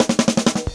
Slow_Roll_160.wav